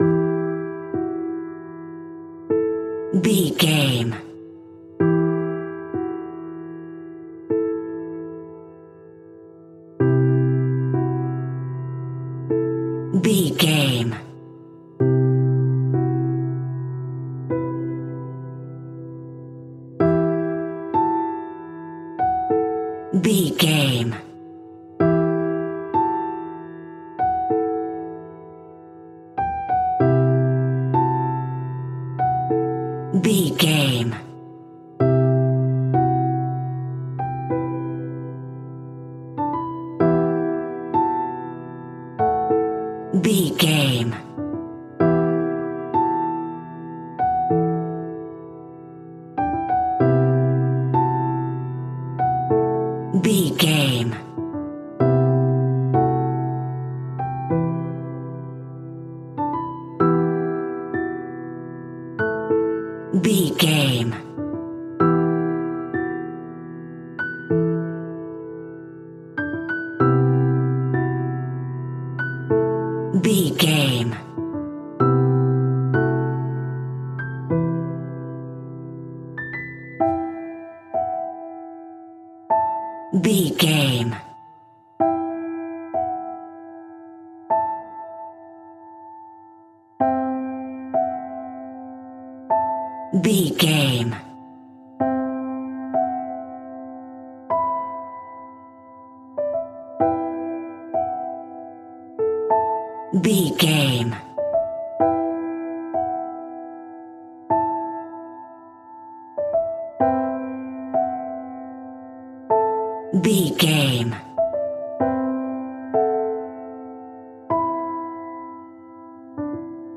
An ethereal and atmospheric piano based music track.
Regal and romantic, a classy piece of classical music.
Aeolian/Minor
WHAT’S THE TEMPO OF THE CLIP?
soft